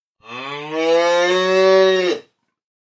دانلود صدای گاو در مزرعه از ساعد نیوز با لینک مستقیم و کیفیت بالا
جلوه های صوتی